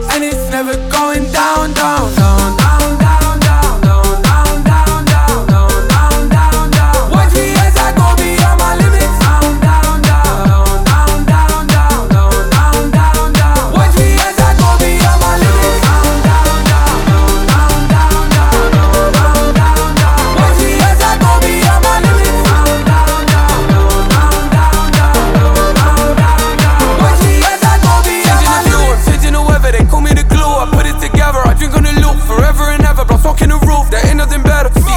2025-06-13 Жанр: Танцевальные Длительность